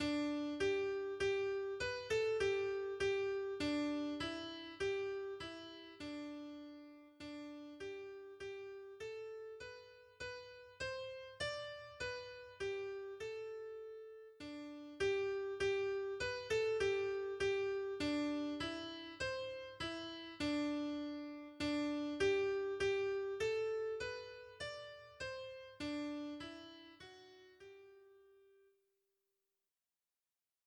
Para aprender la melodía os dejo estos MIDIS, con la voz principal destacada por encima del resto.
en-la-mas-fria-noche-midi-s.mp3